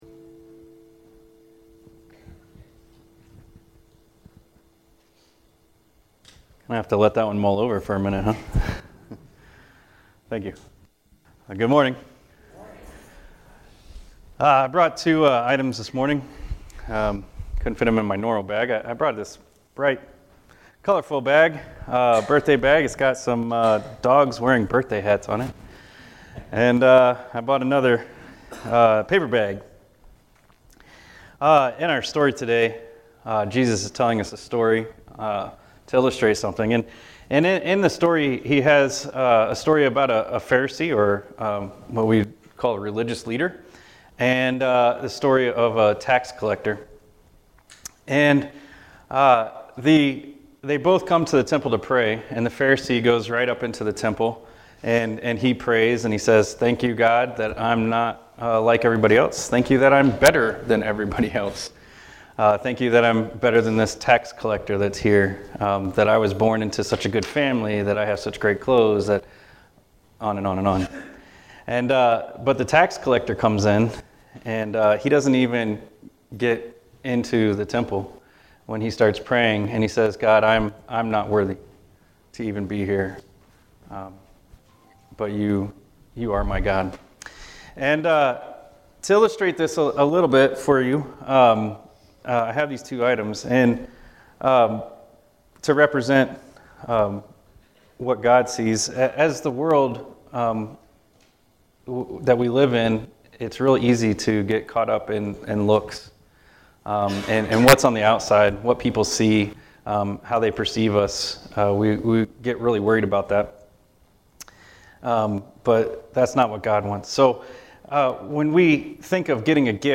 (THE RECORDING WAS DELAYED, STARTING IN THE MIDDLE OF POINT ONE)